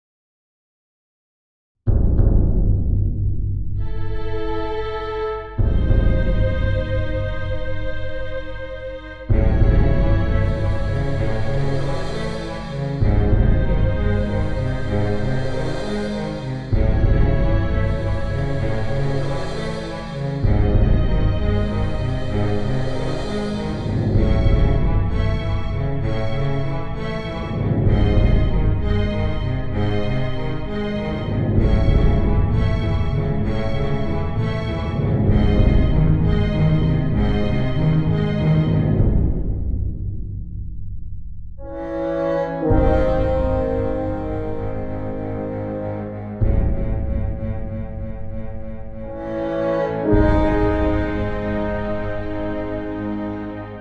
I think my mastering isn’t that great, but it’s very much a first cut.